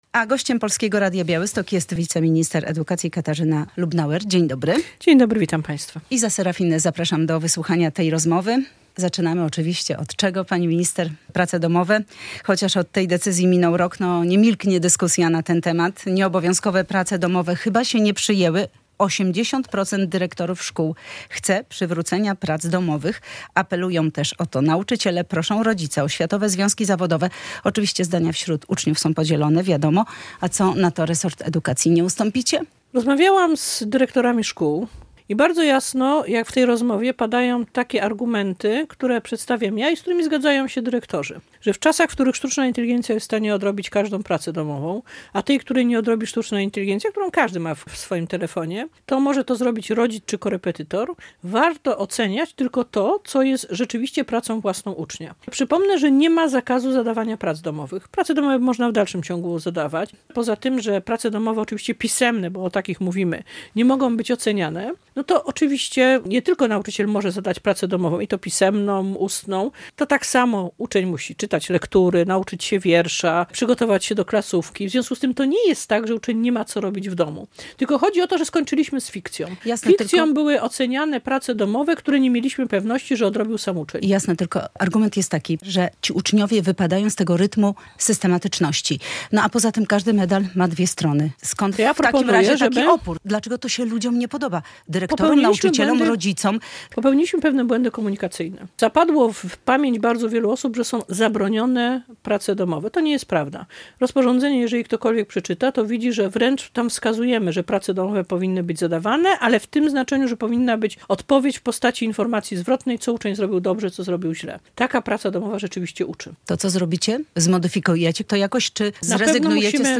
Radio Białystok | Gość | Katarzyna Lubnauer [wideo] - wiceminister edukacji
wiceminister edukacji